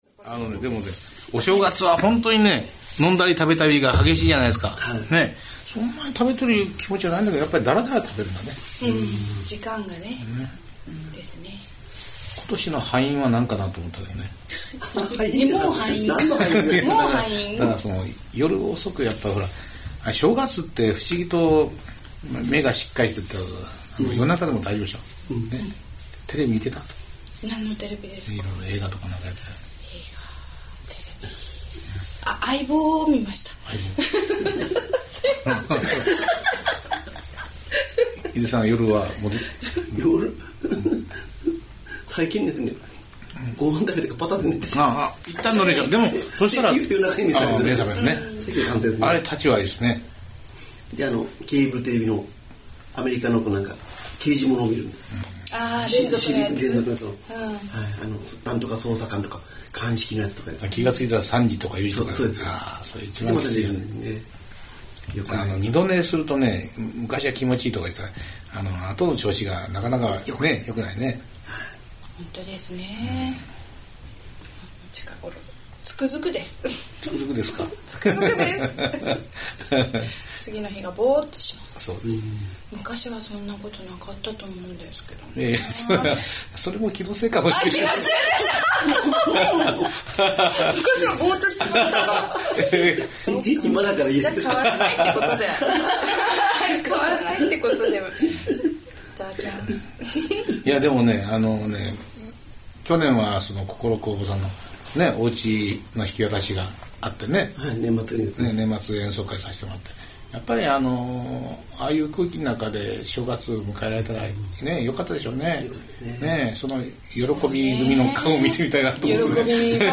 お酒も進んできて、少し酔っ払ってきたのでしょうか・・・いいペースな会話になってきました。